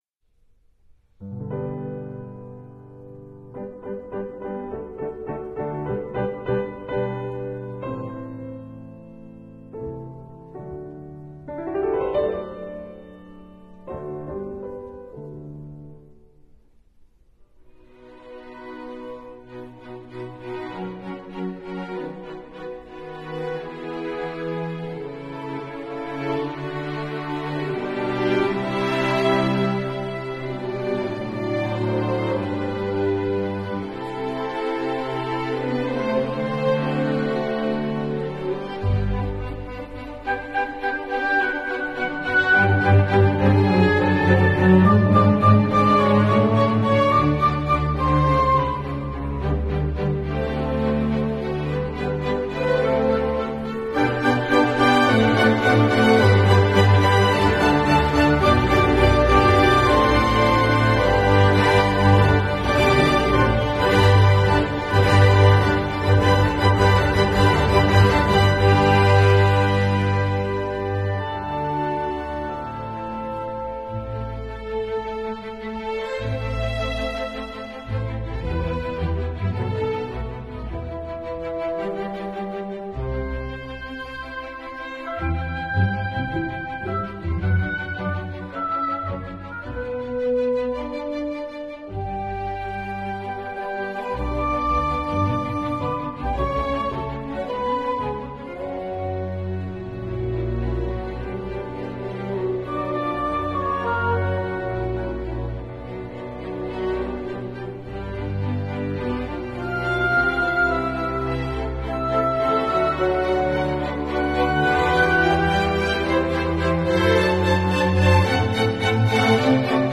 Today we listen to two movements of Beethoven’s Piano Concerto number 4, and then we follow with Schumann’s only Piano Concerto – Romanticism at its best – heartbreaking emotion and ecstasy! Ludwig von Beethoven: Piano Concerto No. 4 in G Major, Op. 58 Performed by Stephen Hough with the Finnish Radio Symphony Orchestra conducted by Hannu Lintu.